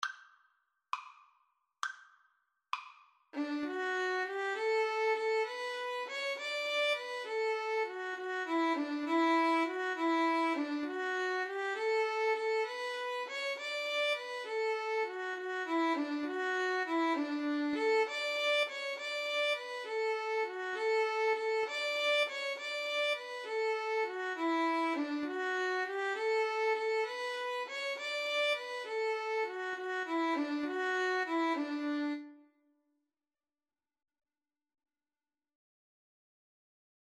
6/8 (View more 6/8 Music)
Violin Duet  (View more Easy Violin Duet Music)